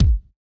ORG Kick.wav